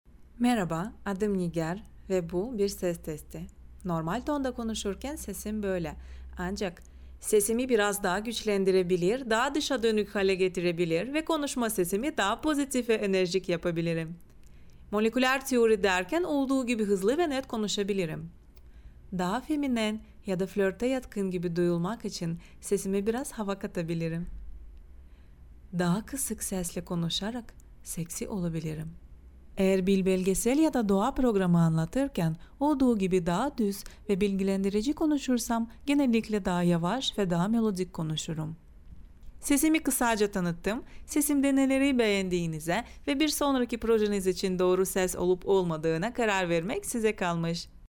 课件解说